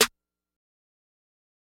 Metro Snare13.wav